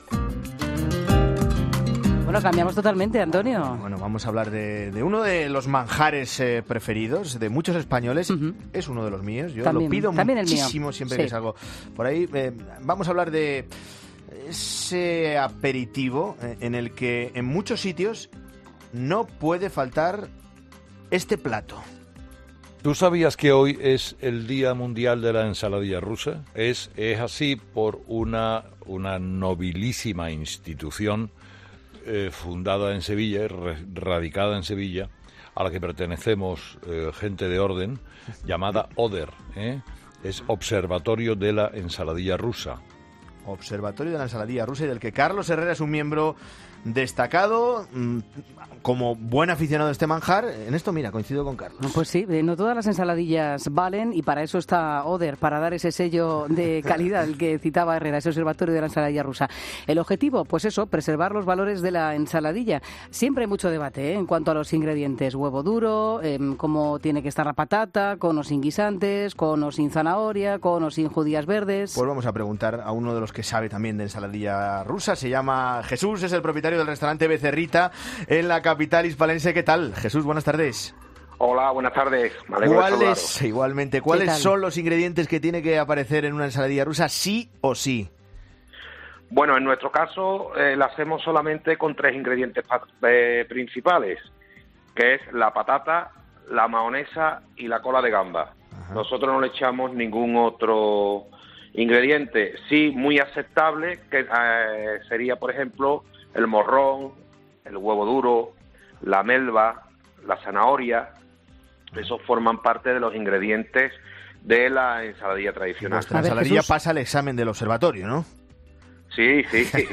En Mediodía COPE hablamos con el propietario de un restaurante que pertenece al Observatorio de la Ensaladilla Rusa
Su reacción fue rápida “Lo denuncie automáticamente”, comentaba entre risas.